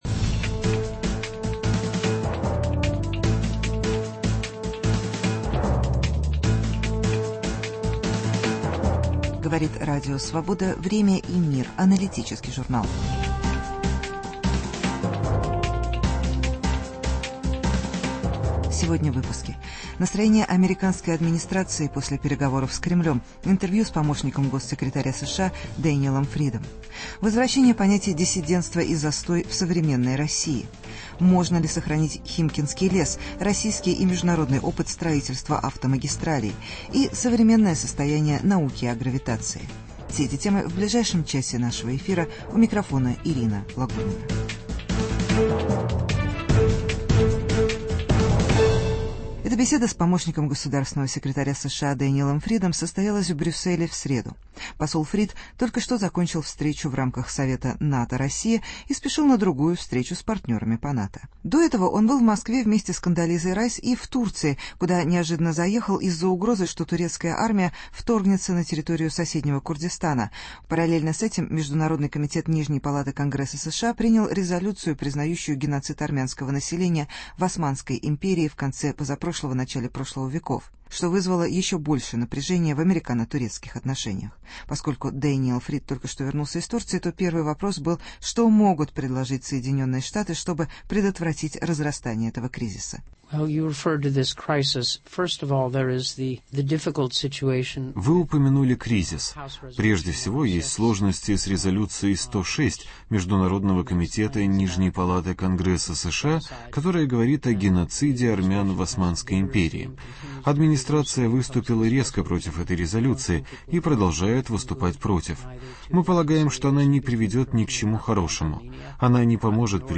Интервью с Дэниэлом Фридом, помощником госсекретаря США.